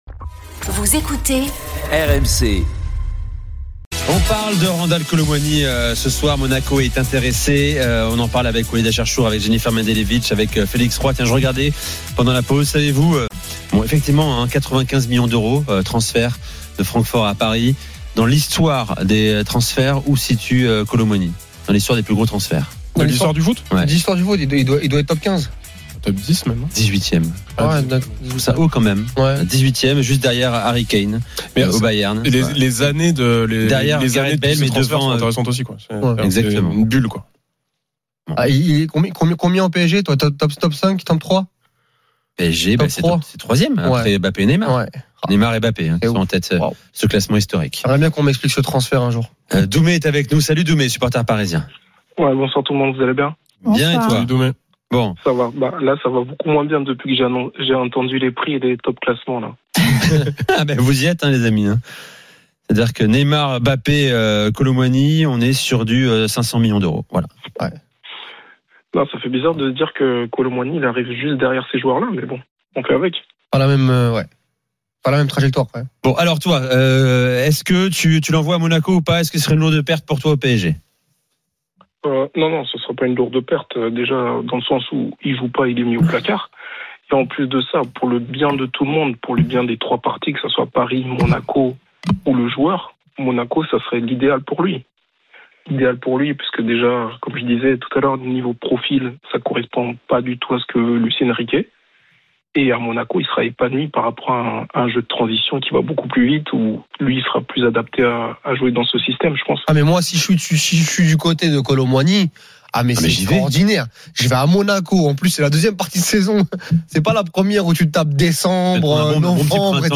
Chaque jour, écoutez le Best-of de « Génération After » sur RMC, l'émission composée de chroniqueurs qui ont grandi avec l’After.
Au programme, des débats passionnés entre experts et auditeurs RMC, ainsi que de nombreux invités.